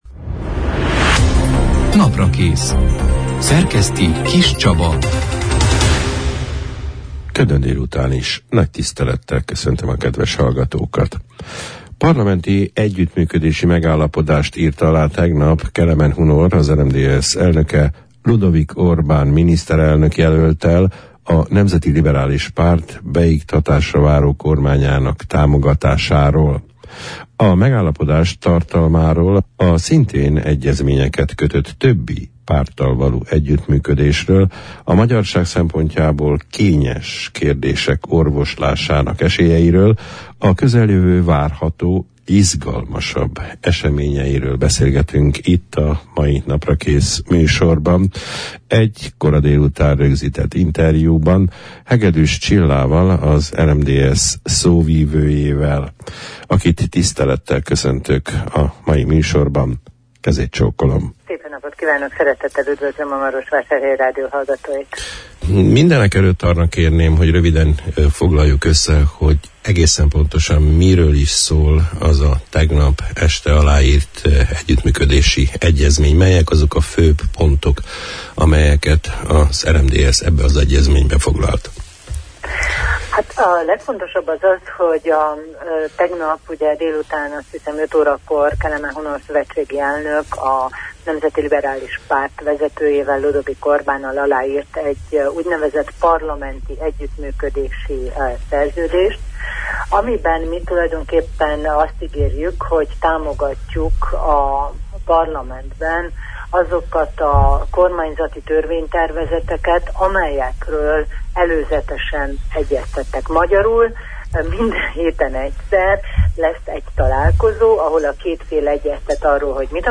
egy kora délután rögzített interjúban